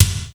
BIG BD 4.wav